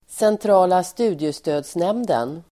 Uttal: [sentr'a:la ²st'u:diestö:dsnem:den]